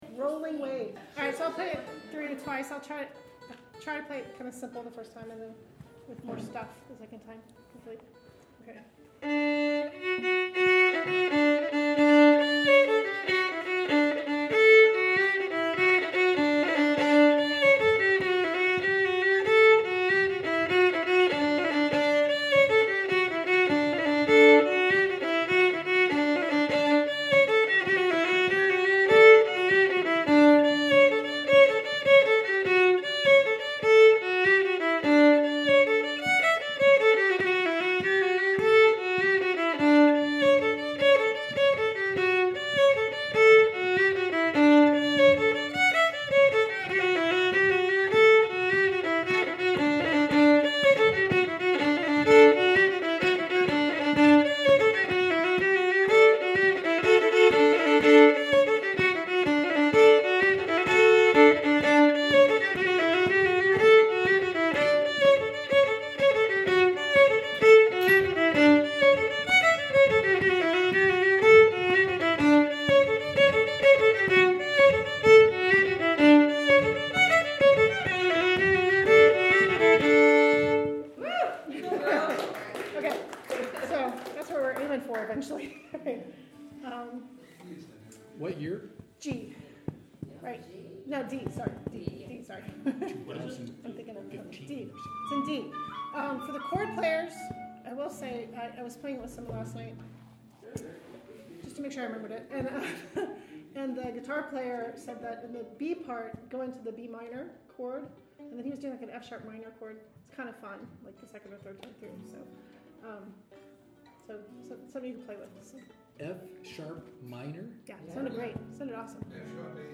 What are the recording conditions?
click on score to listen to the tune This tune was taught to the group as a "Mystery Tune" on Sept. 8, 2010...hear the lesson...